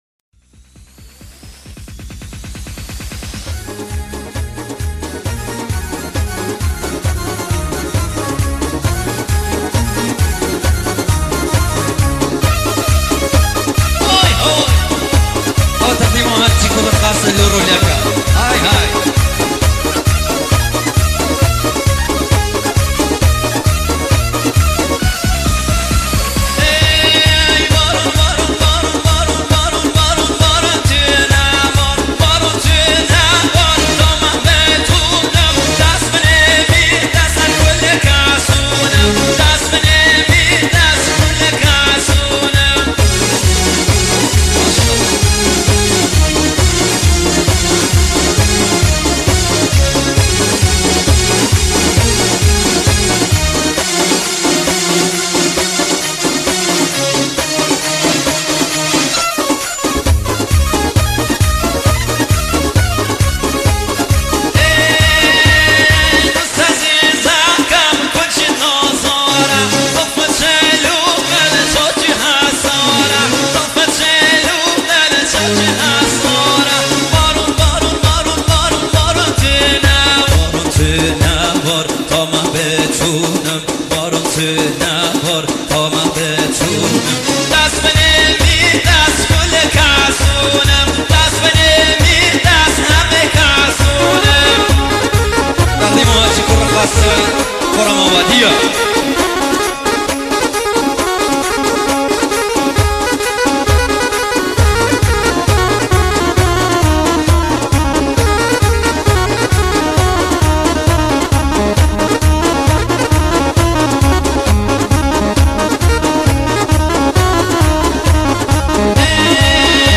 آهنگ لری شاد
آهنگ لکی شاد